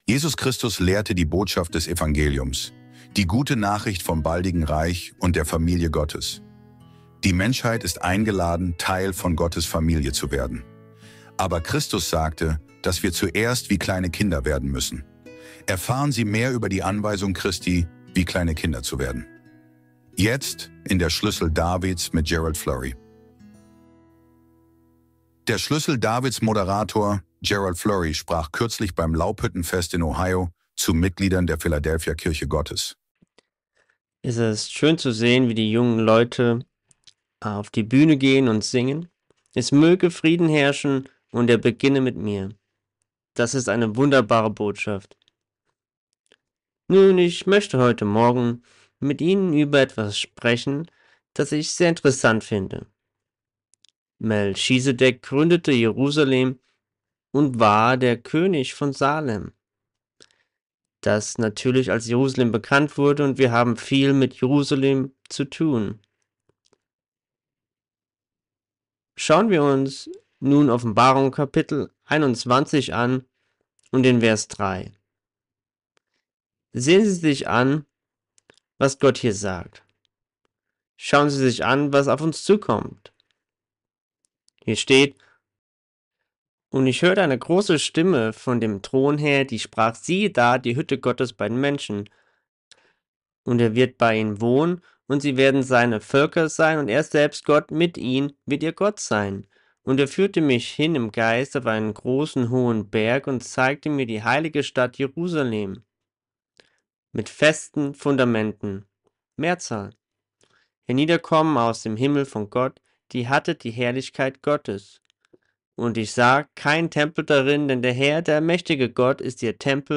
Dies ist das Audioformat für seine Fernsehsendung The Key of David (Der Schlüssel Davids) in Deutsch.